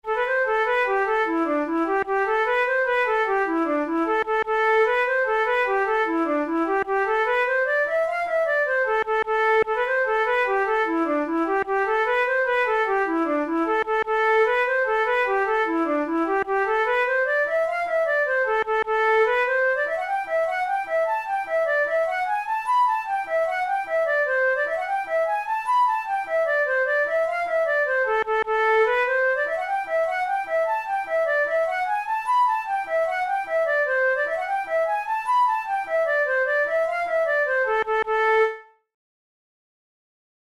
InstrumentationFlute solo
KeyA minor
Time signature6/8
Tempo100 BPM
Jigs, Traditional/Folk